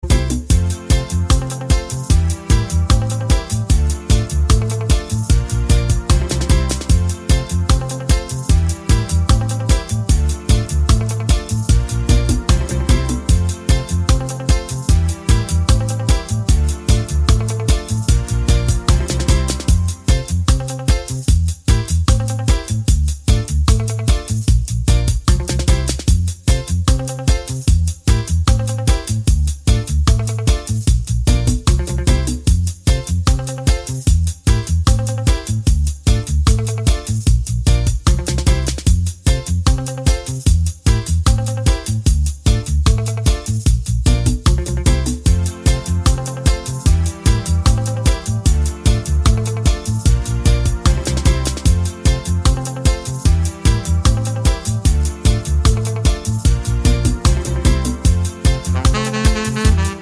no Backing Vocals Reggae 4:12 Buy £1.50